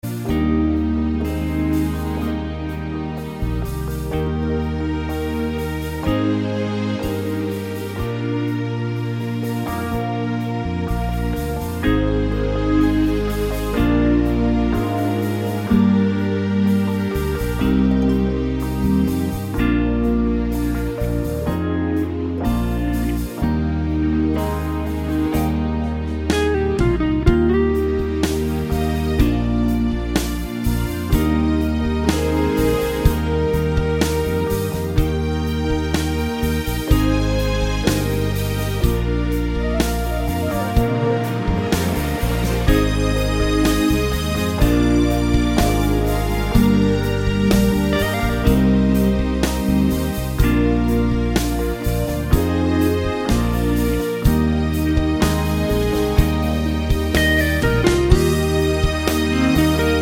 no Backing Vocals Christmas 3:57 Buy £1.50